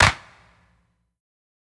CLAP_SAD.wav